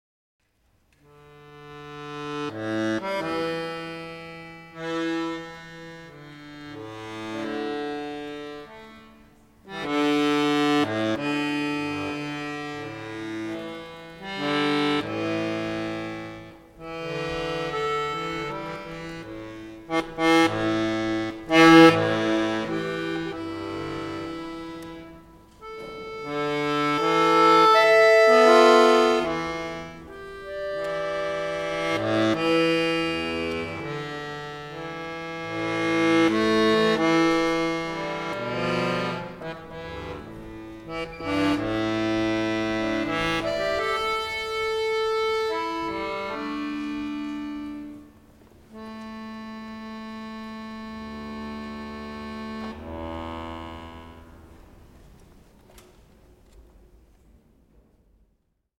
for guitar, accordion